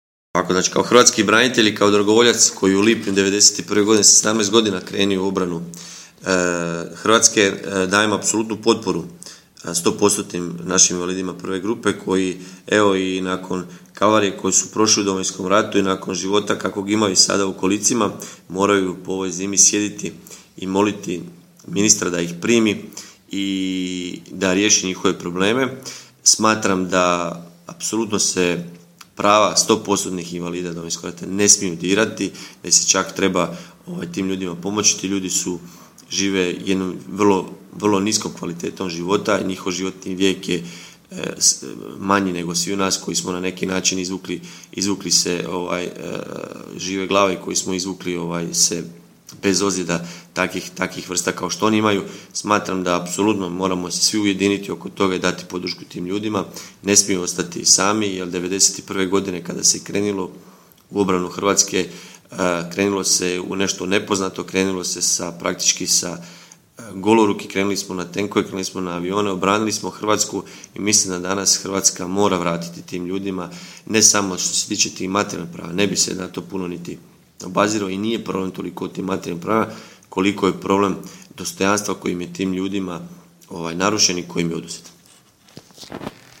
izjava-ivan-anusic.mp3